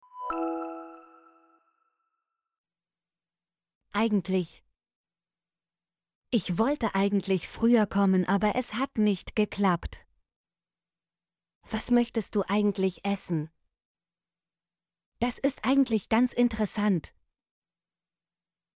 eigentlich - Example Sentences & Pronunciation, German Frequency List